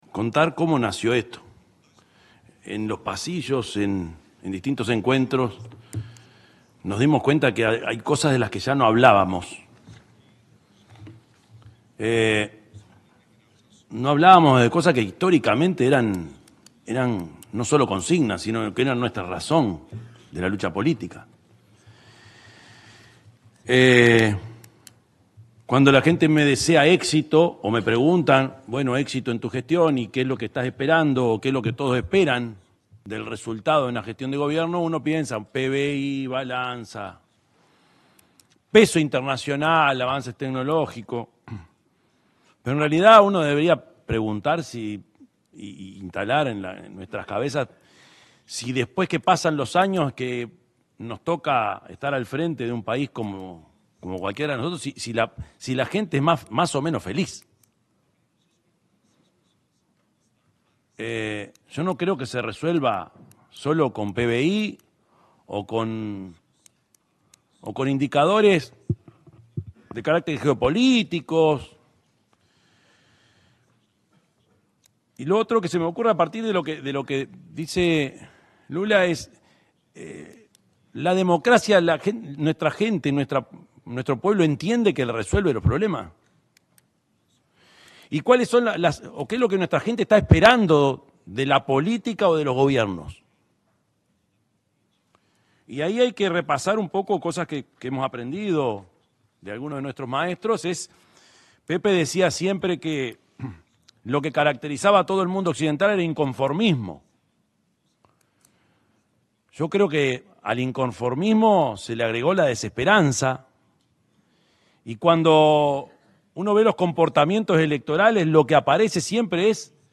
Palabras del presidente Orsi en encuentro En Defensa de la Democracia: Lucha contra el Extremismo
El presidente de la República, Yamandú Orsi, expuso en el encuentro En Defensa de la Democracia: Lucha contra el Extremismo, junto con sus pares de